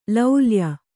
♪ laulya